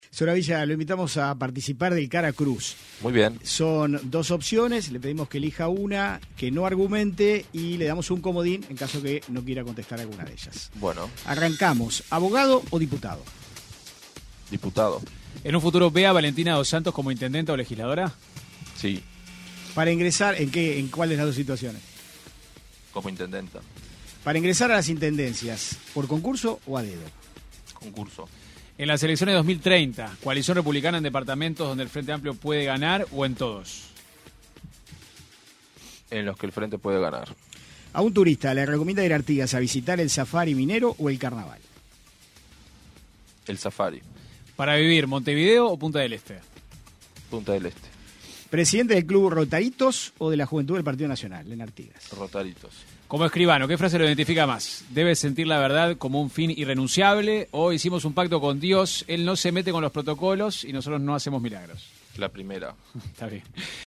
El intendente electo de Artigas, Emiliano Soravilla, en la sección Cara o Cruz de 970 Noticias, aseguró que en un futuro ve a Valentina Dos Santos, designada secretaria general de la comuna artiguense, como intendenta.